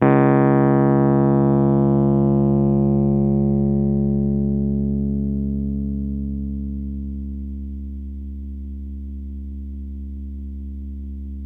RHODES CL03R.wav